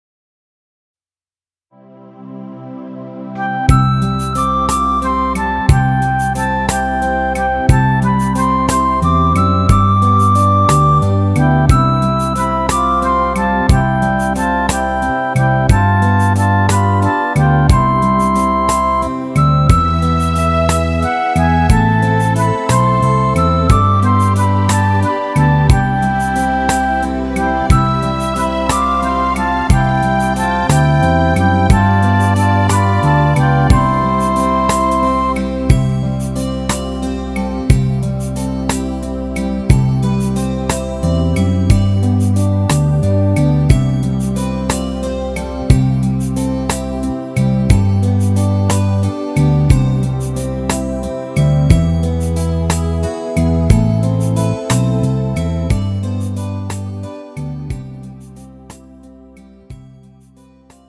음정 C 키
장르 가요 구분 Pro MR